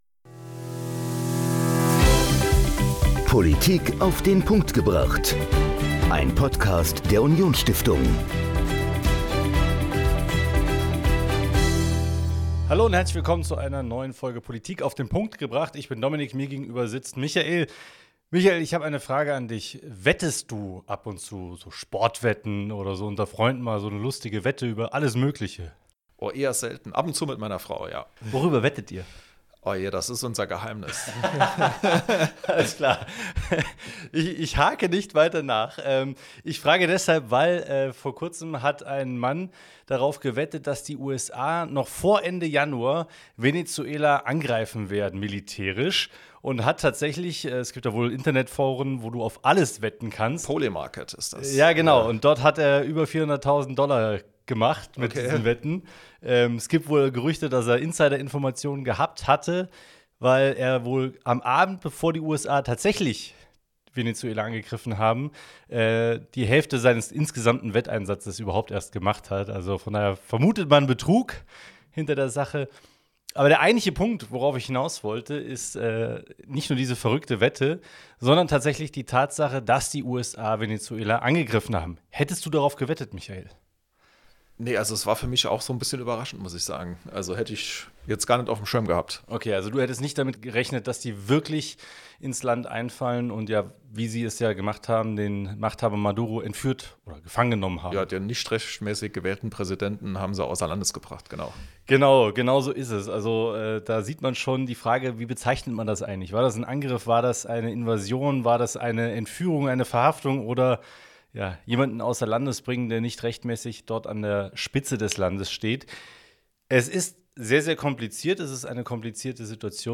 Journalist und Lateinamerikakorrespondent ordnet die Lage und die Folgen für die Welt ein.